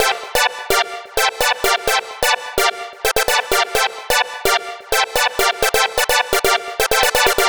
Lead 128-BPM G.wav